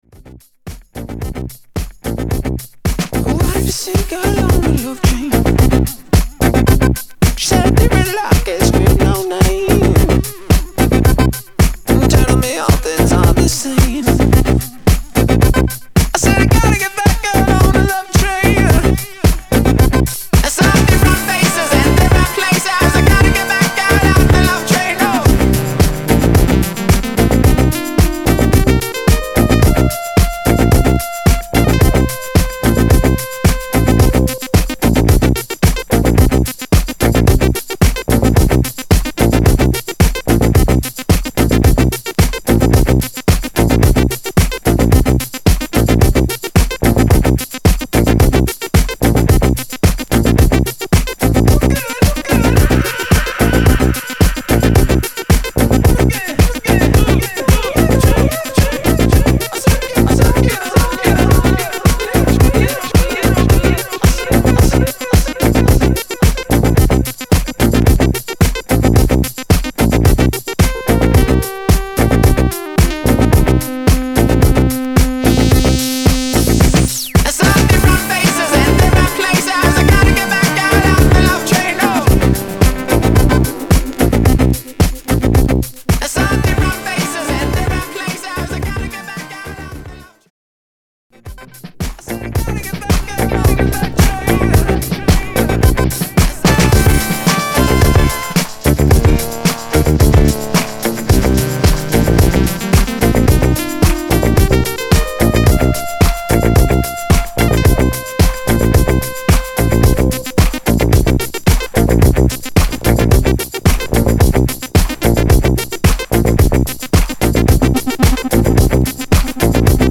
試聴は"VOCAL" "DUB"です。